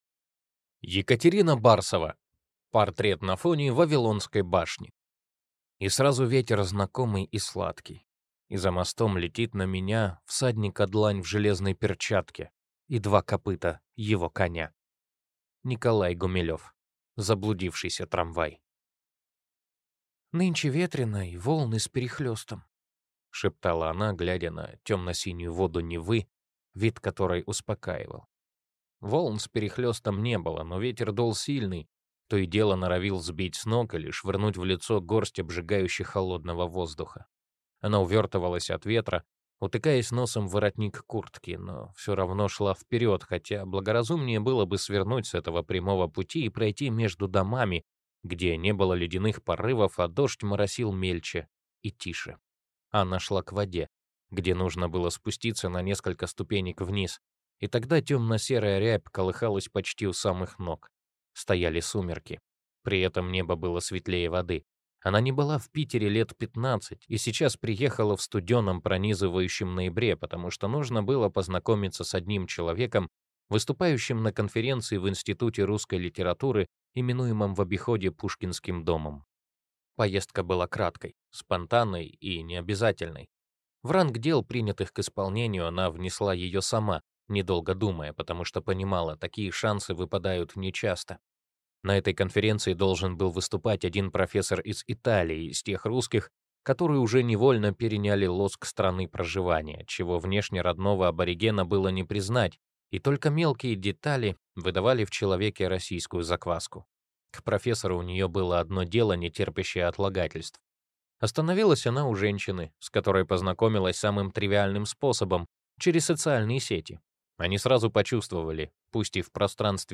Аудиокнига Детективная осень | Библиотека аудиокниг